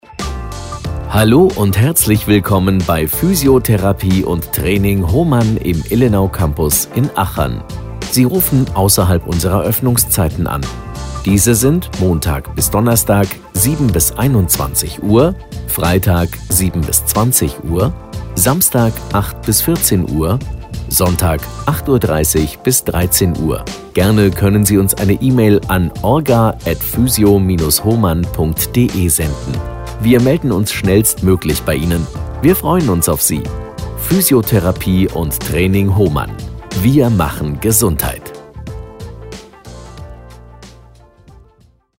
Telefonansage Praxis für Physiotherapie
Anrufbeantworter Ansage – Praxis für Physiotherapie Hohmann